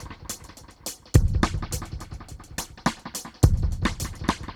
Index of /musicradar/dub-drums-samples/105bpm
Db_DrumsB_EchoKit_105-01.wav